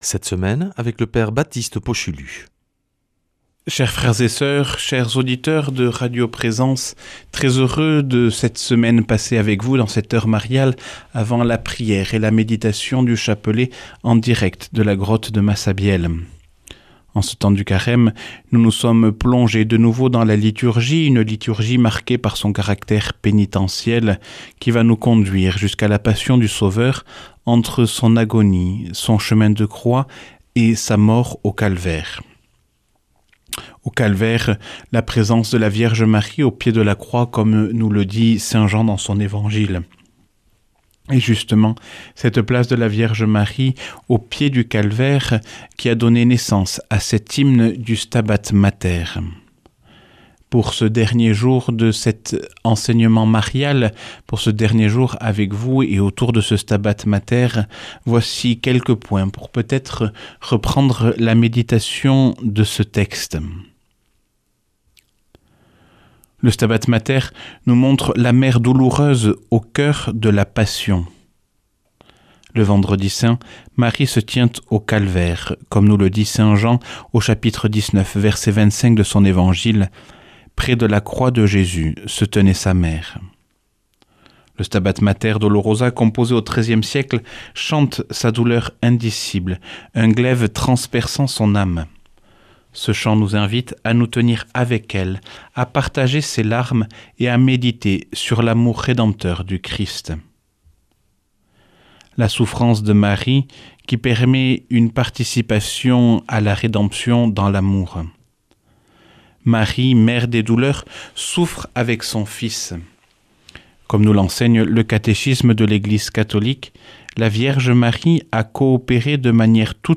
vendredi 13 mars 2026 Enseignement Marial Durée 10 min